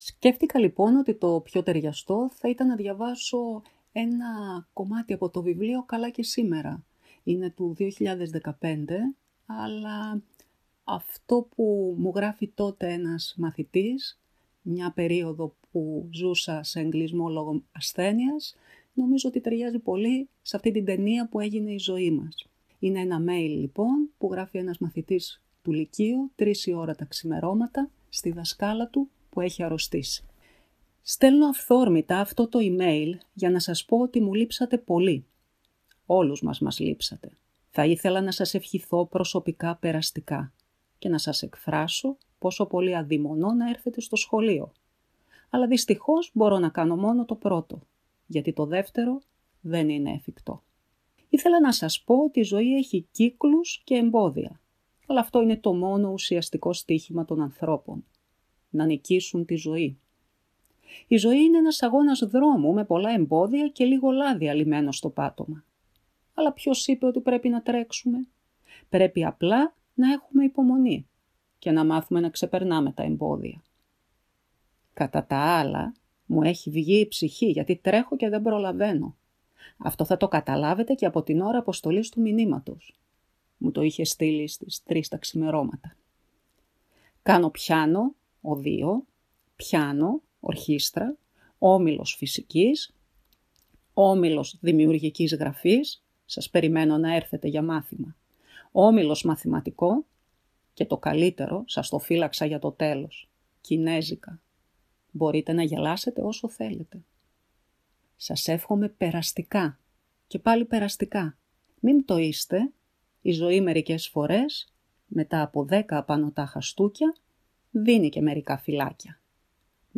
Διαβάζει απόσπασμα από το βιβλίο της «Καλά και σήμερα»,  εκδ. Μεταίχμιο